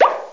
Amiga 8-bit Sampled Voice
1 channel
pop.mp3